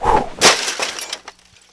trash.wav